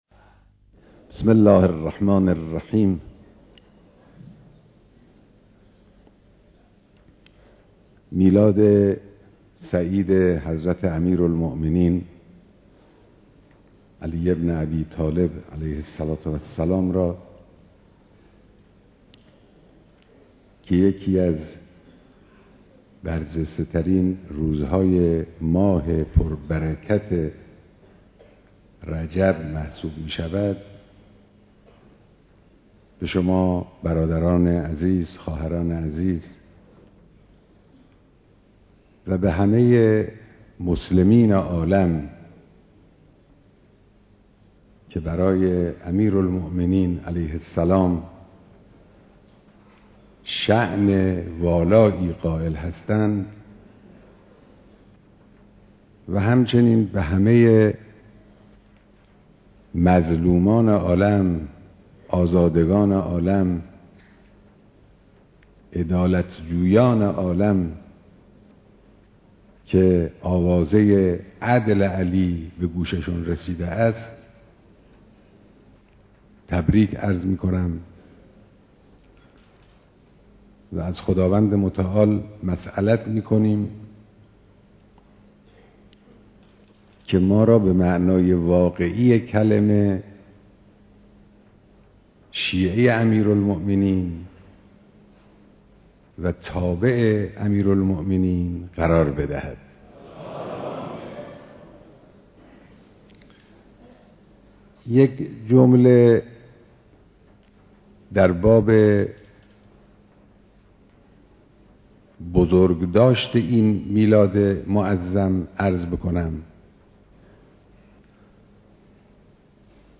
دیدار قشرهای مختلف مردم به مناسبت خجسته سالروز میلاد حضرت علی (ع)